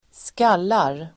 Uttal: [²sk'al:ar]